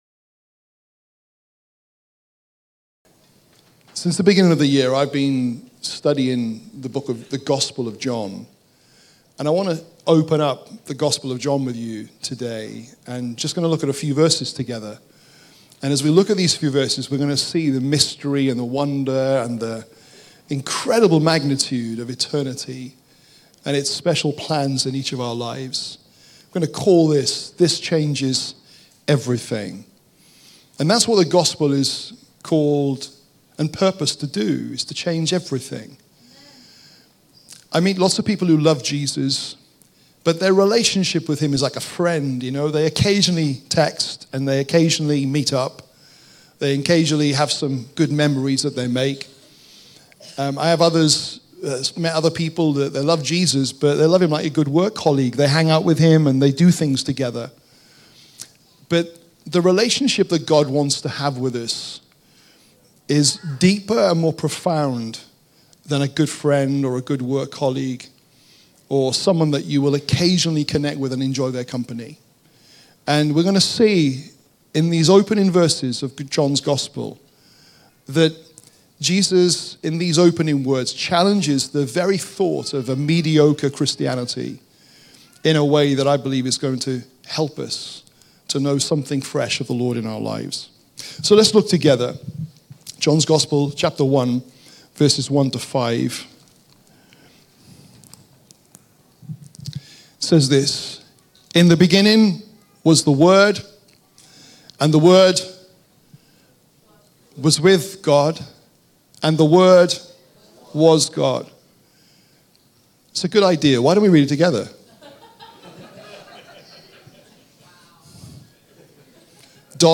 Sunday Messages This Changes Everything